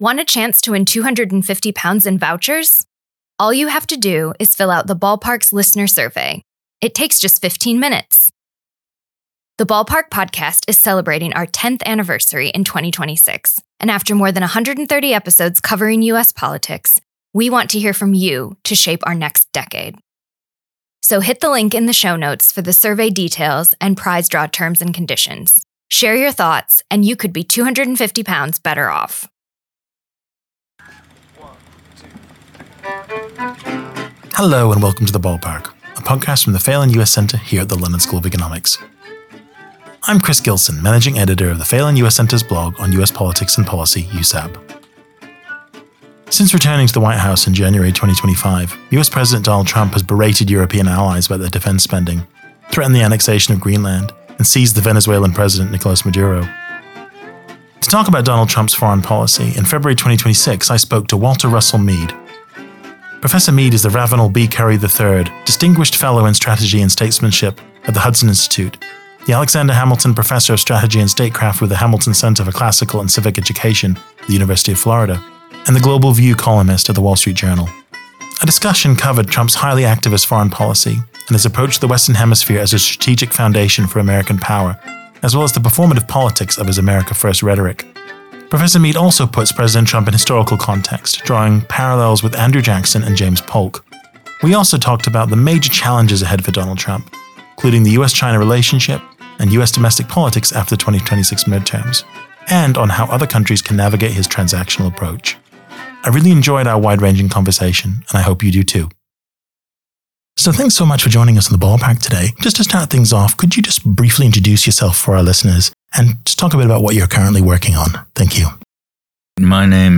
To talk about Donald Trump’s foreign policy, in February 2026 the Phelan US Centre spoke to Walter Russell Mead, the Ravenel B. Curry III Distinguished Fellow in Strategy and Statesmanship at the Hudson Institute, the Alexander Hamilton Professor of Strategy and Statecraft with the Hamilton Center for Classical and Civic Education at the University of Florida, and the "Global View" columnist at the Wall Street Journal.